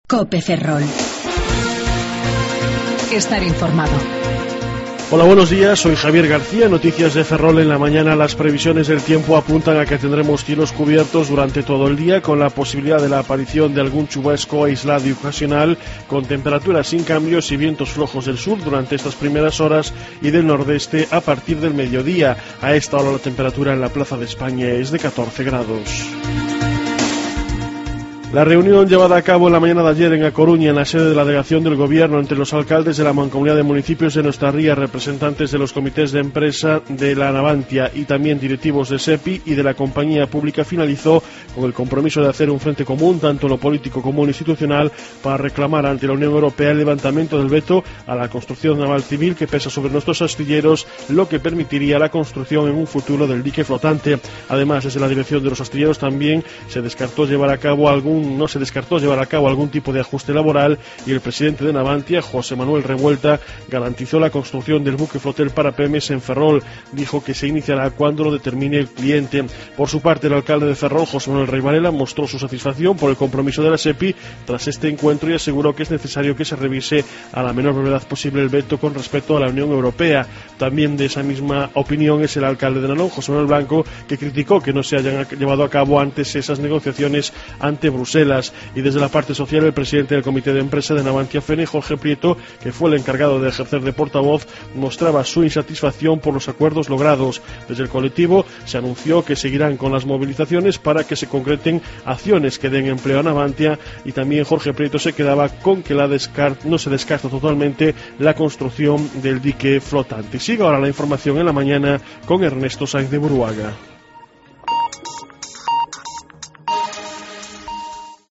08:28 Informativo La Mañana